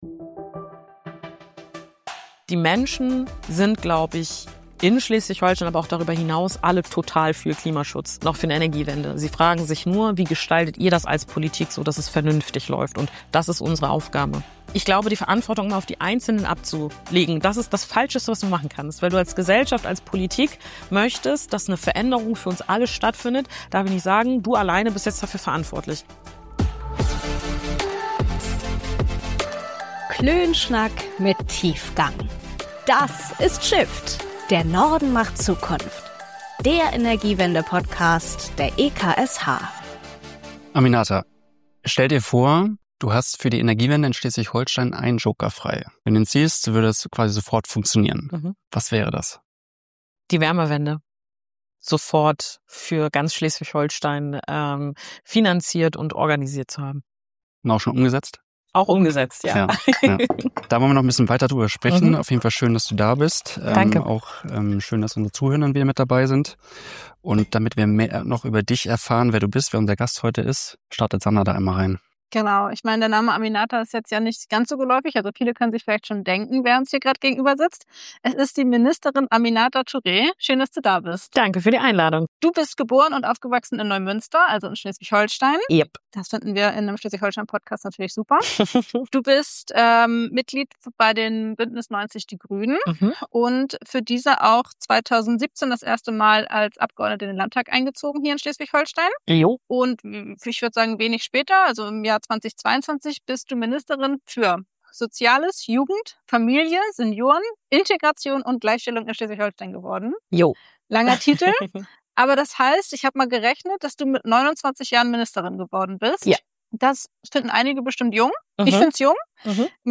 Diese Frage diskutieren wir heute mit der Grünen-Politikerin Aminata Touré.